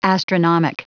Prononciation du mot astronomic en anglais (fichier audio)
Prononciation du mot : astronomic